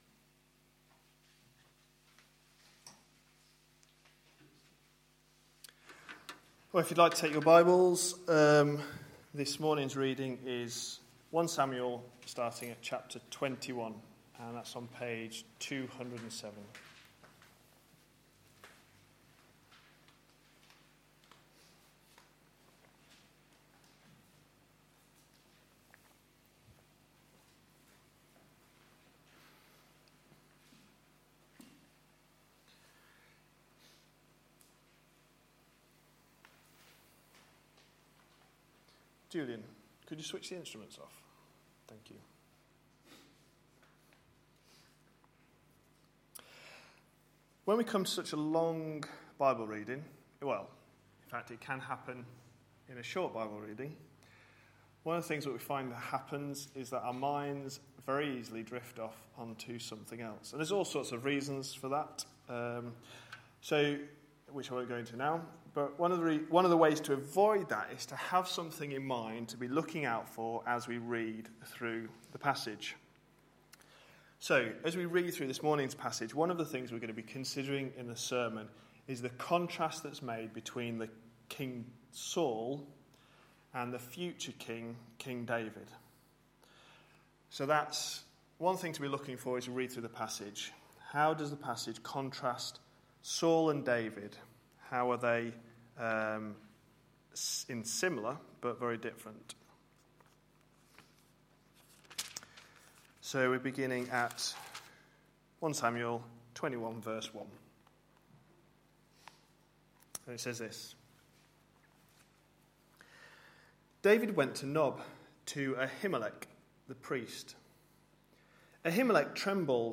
A sermon preached on 28th July, 2013, as part of our God's King? series.